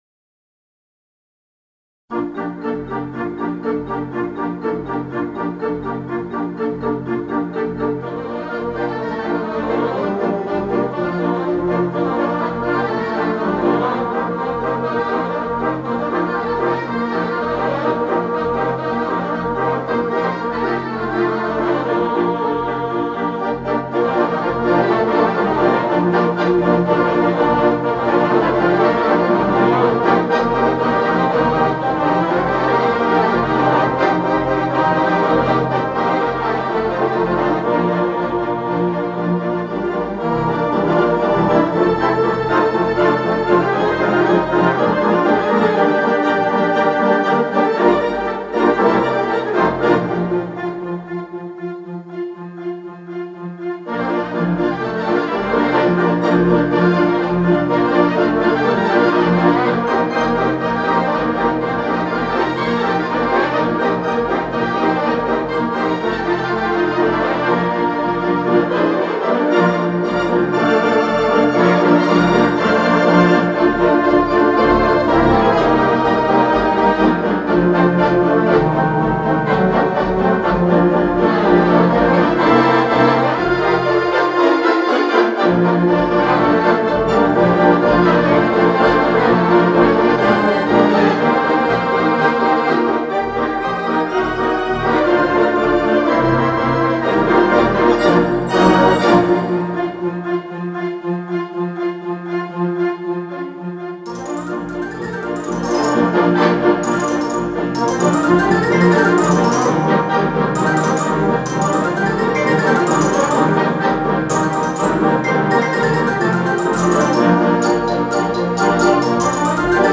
Digital Theatre Organ
The Second Concert